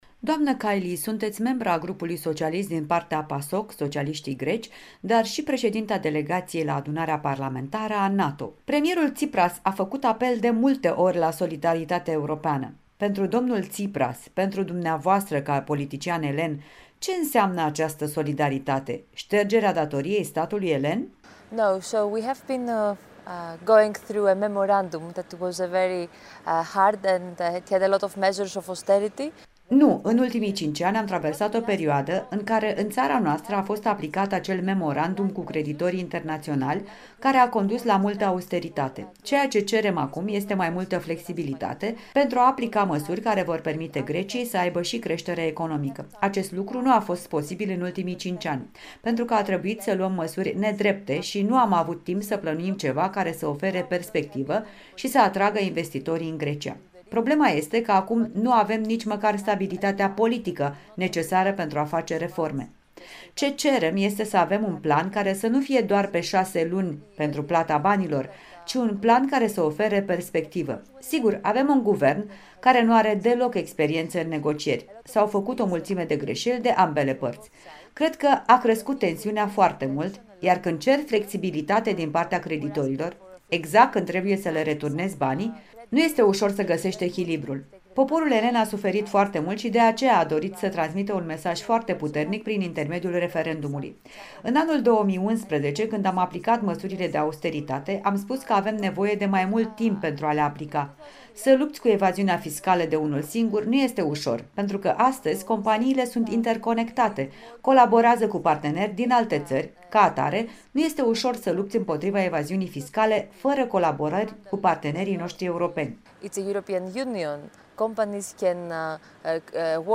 Interviu cu europarlamentara elenă Eva Kaili